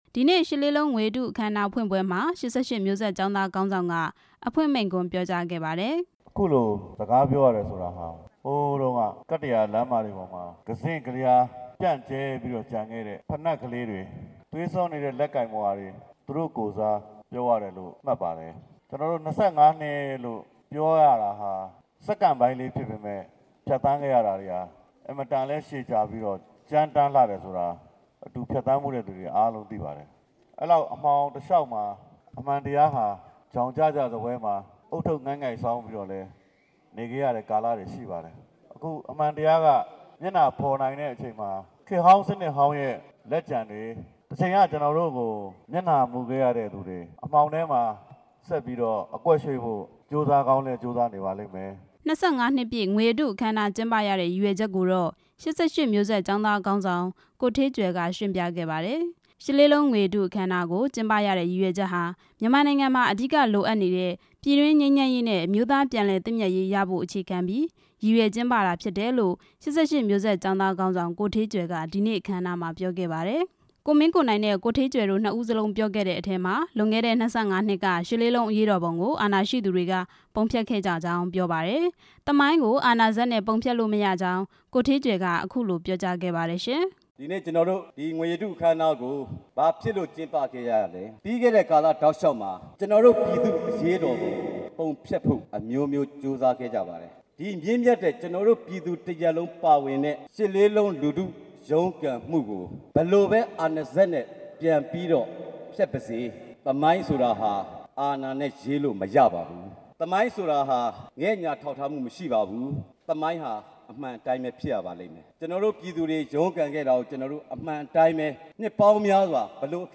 ရှစ်လေးလုံး ငွေရတု အခမ်းအနားပွဲများ တင်ပြချက်